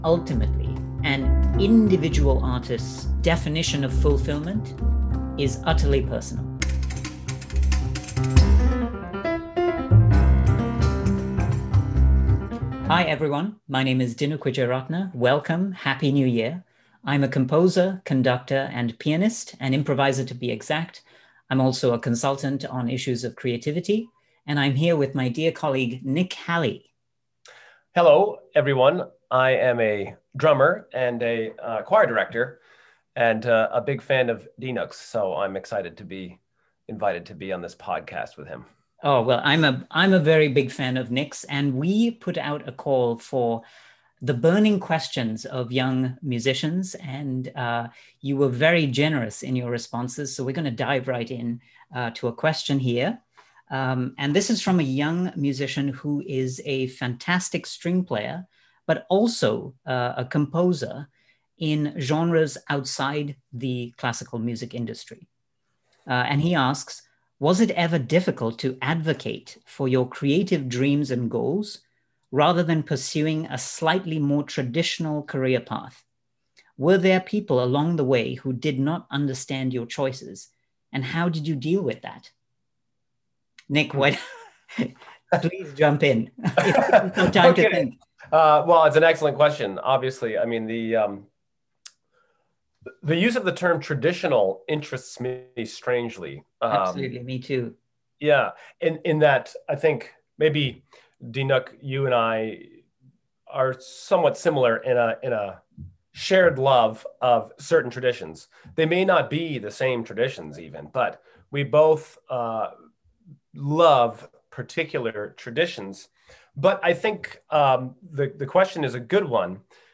I am privileged to have a conversation